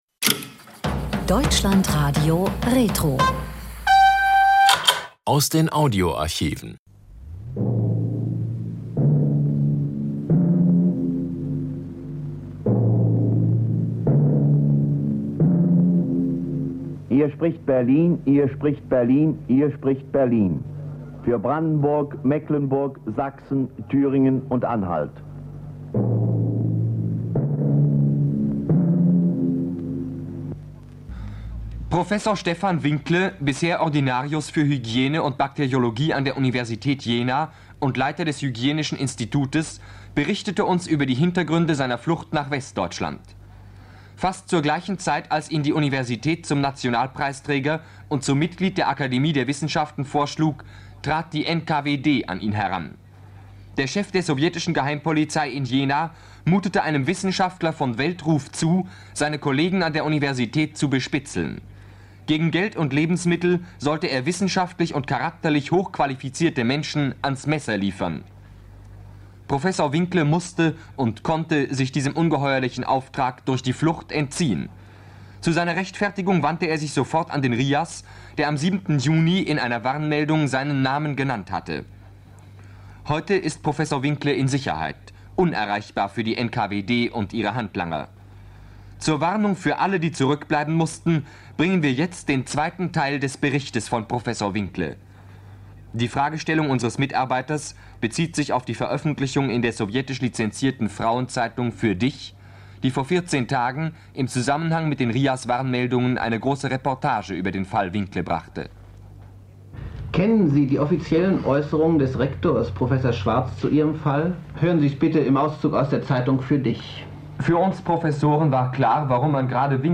Flucht in den Westen, weil er für die sowjetische Geheimpolizei Kolleg:innen bespitzeln sollte: Gespräch